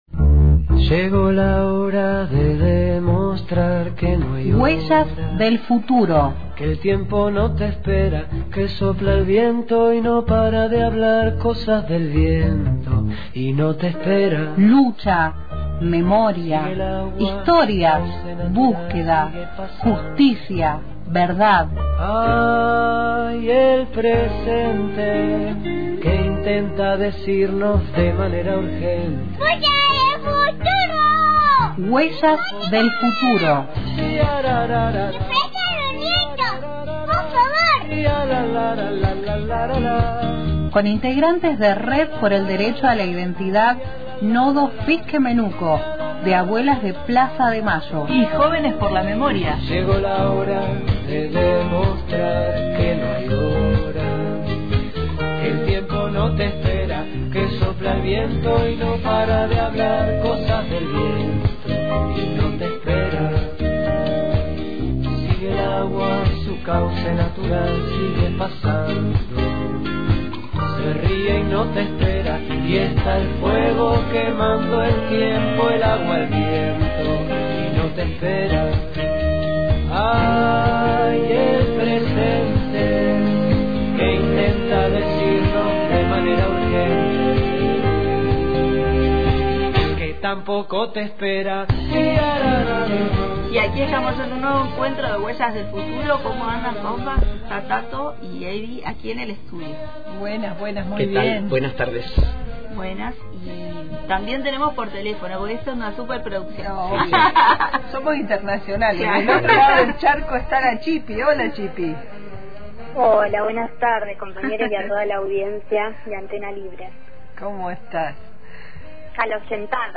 Además, se sumó vía telefónica